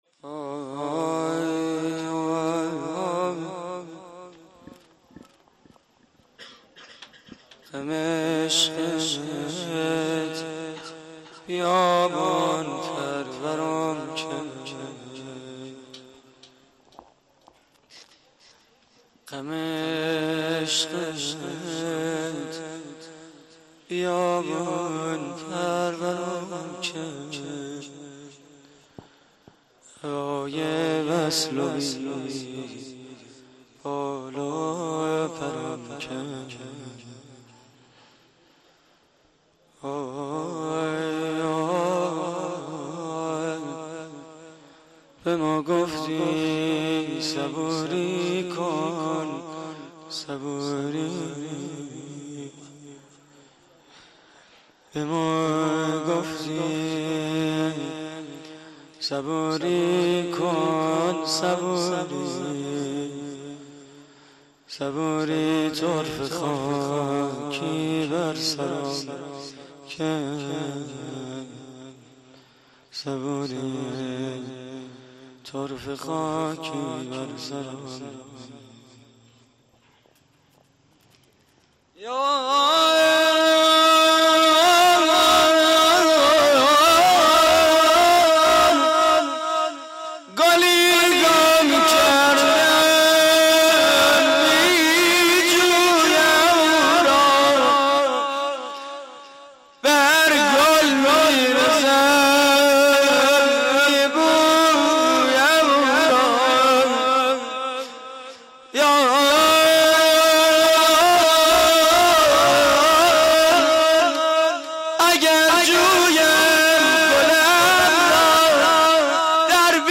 روضه پایانی: غم عشقت بیابون پرورم کرد
مراسم عزاداری شام غریبان حسینی / هیئت محبان العباس (ع) – علی‌آباد؛ 6 دی 88